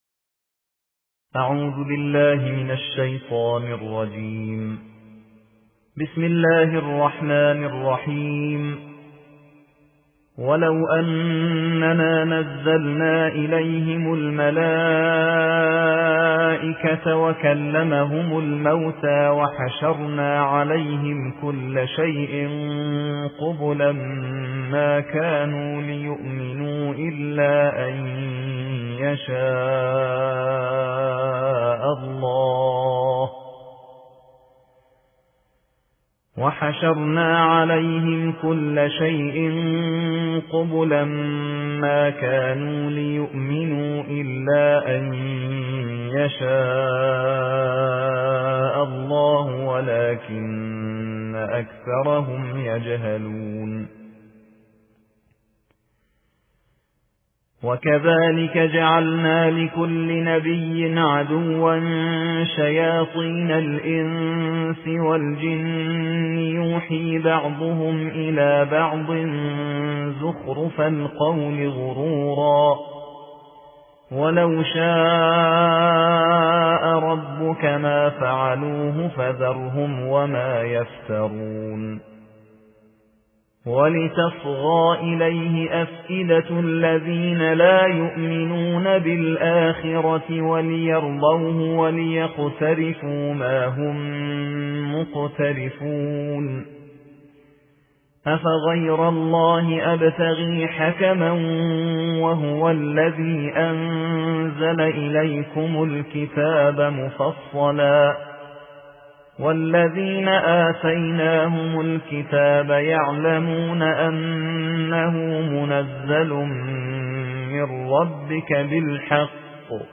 قرائت قرآن